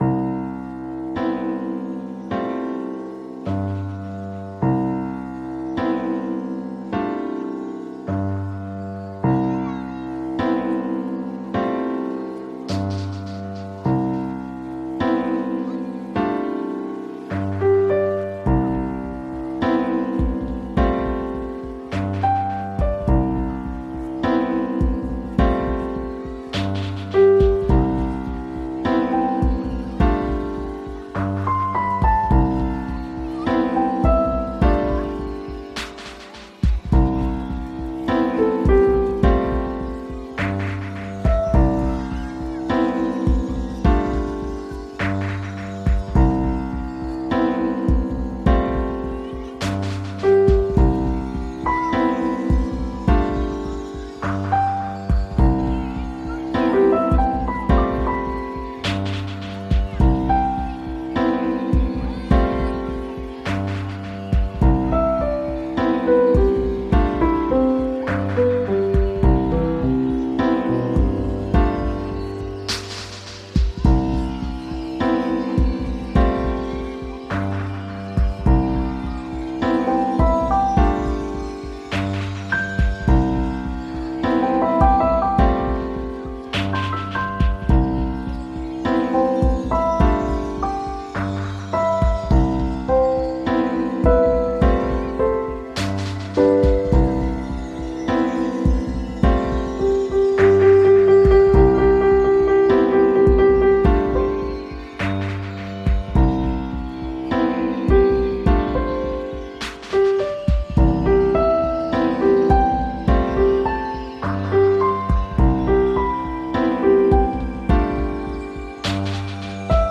> NU DISCO・BALEARIC・NU JAZZ・CROSSOVER・REGGAE
【7"INCH】(レコード)